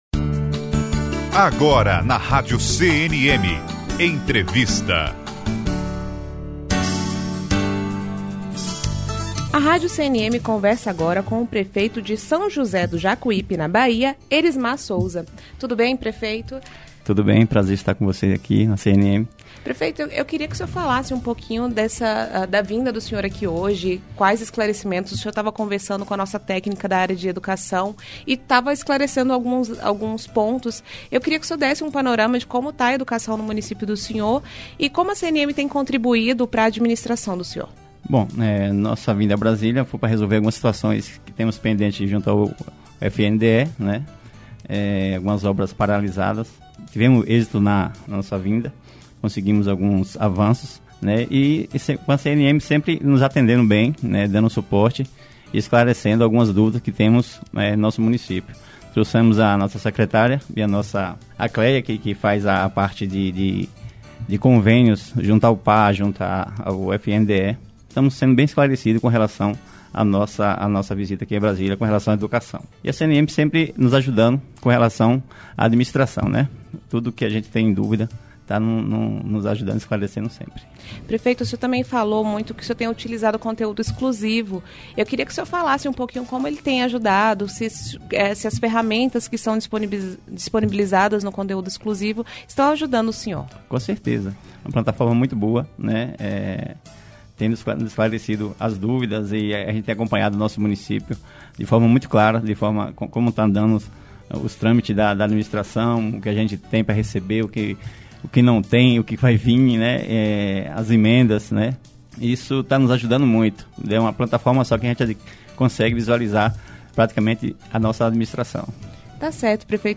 Entrevista - Prefeito Erismar Souza - São José do Jacuípe (BA)
Entrevista---Prefeito-Erismar-Souza---So-Jos-do-Jacupe-BH.mp3